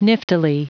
Prononciation du mot niftily en anglais (fichier audio)
Prononciation du mot : niftily